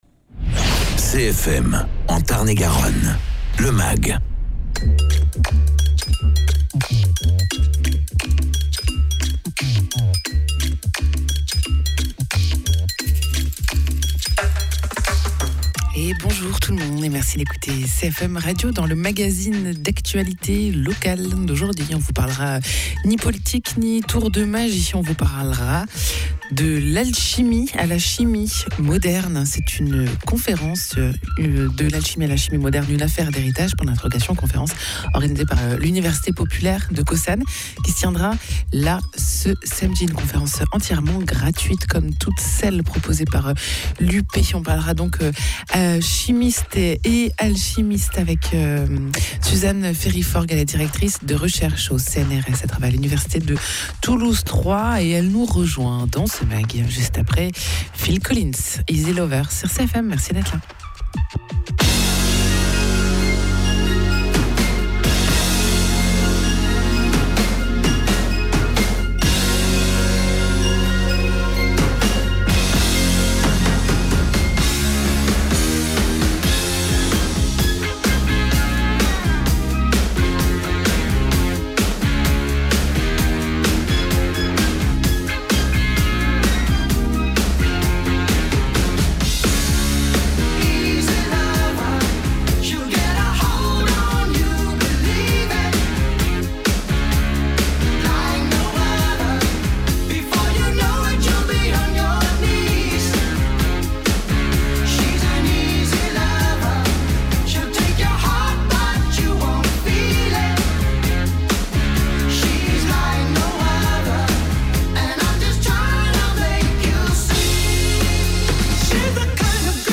De l’alchimie à la chimie conférence à Caussade